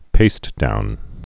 (pāstdoun)